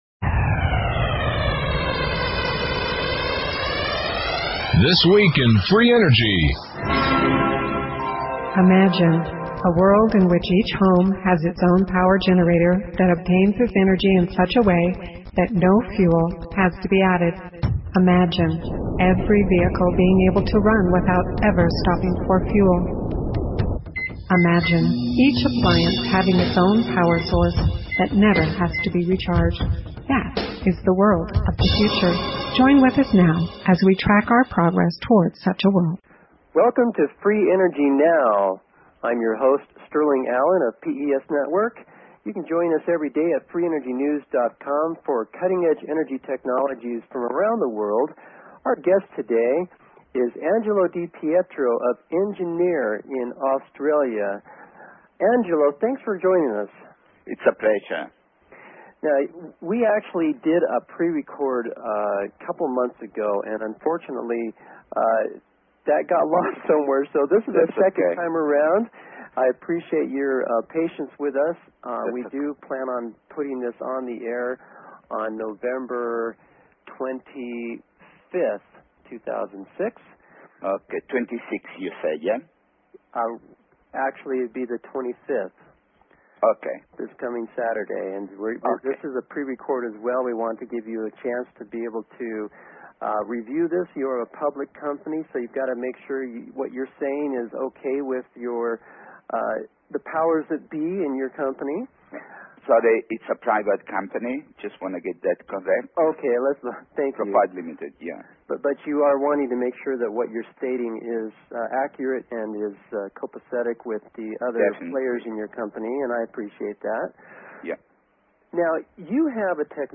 Talk Show Episode, Audio Podcast, Free_Energy_Now and Courtesy of BBS Radio on , show guests , about , categorized as